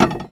R - Foley 31.wav